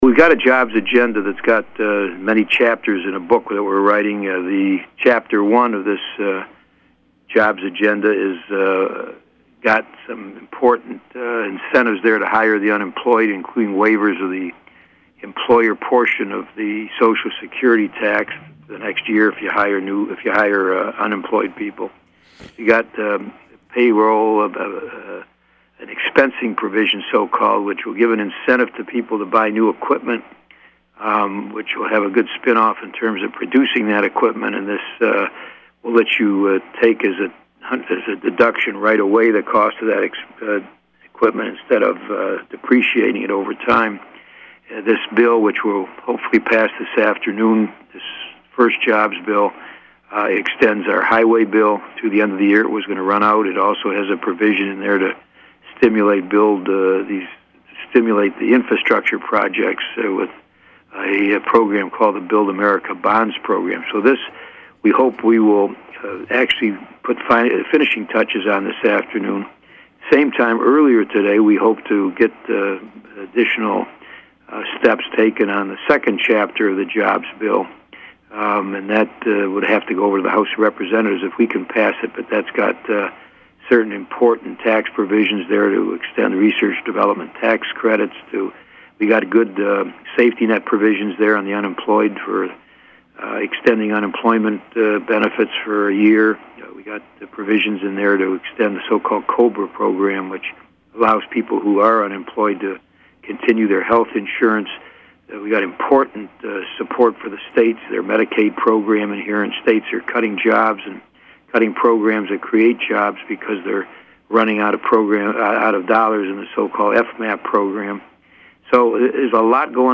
Here are some excerpts from a conversation with United States Senator Carl Levin concerning HIRE legislation currently making it’s way through Congress.